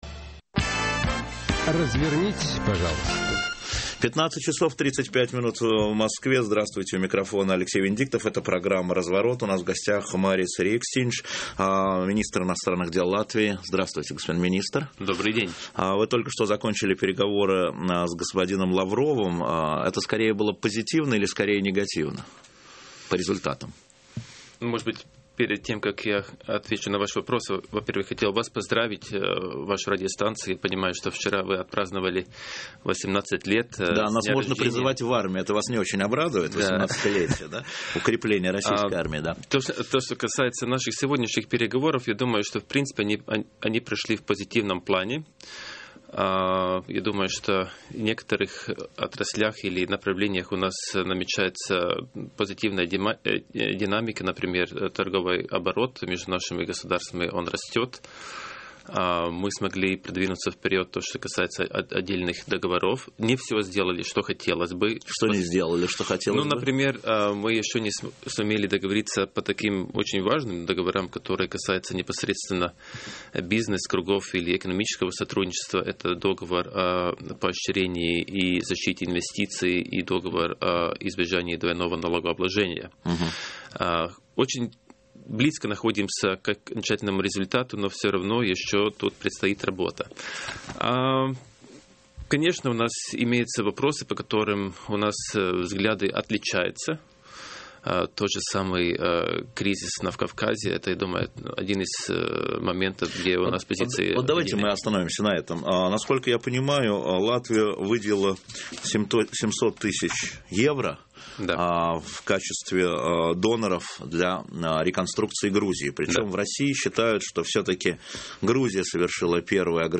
Интервью
Марис Риекстиньш - Интервью - 2008-10-23